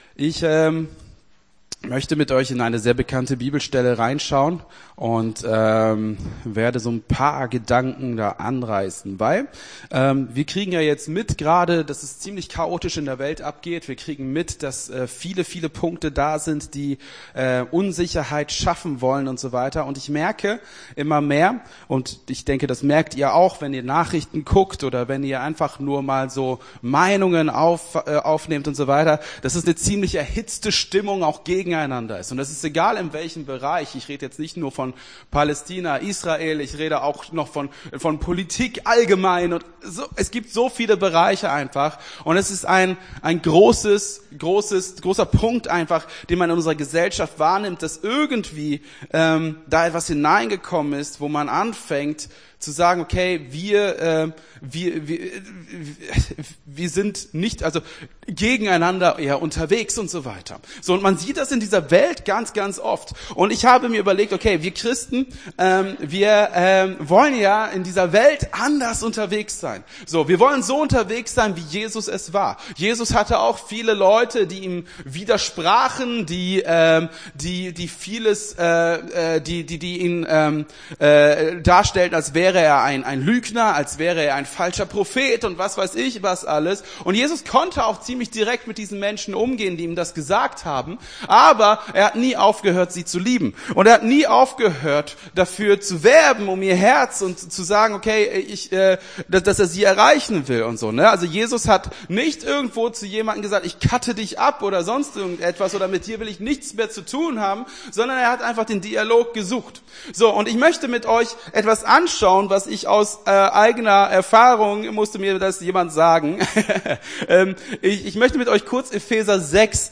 Gottesdienst 26.11.23 - FCG Hagen